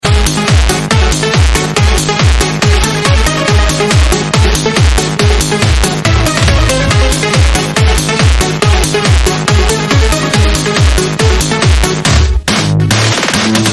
industrial/new age